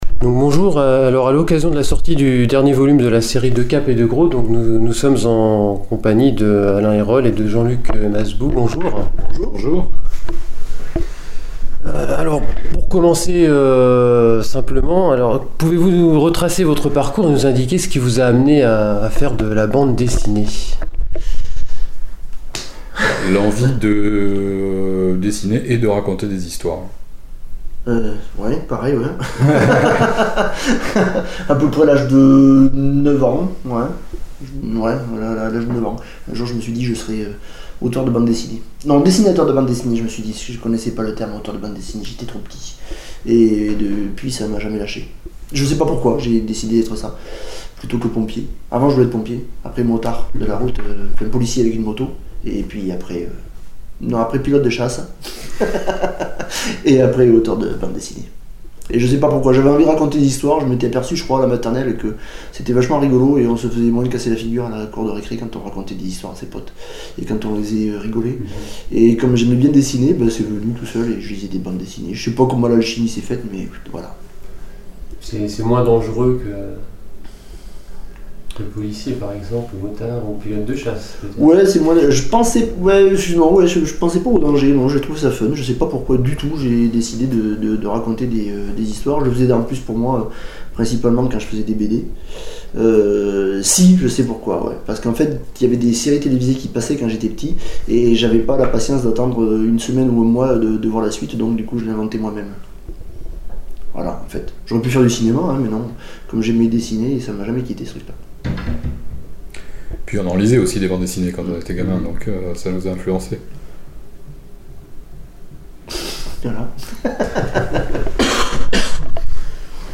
Interview de Cape et de Croc